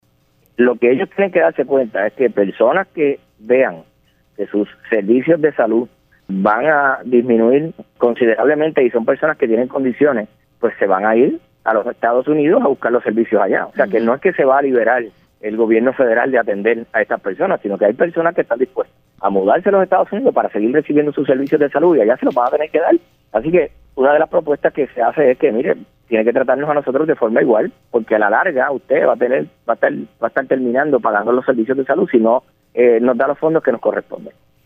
Oiga lo que dijo el Senador